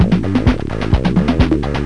1 channel
rhythmloop.mp3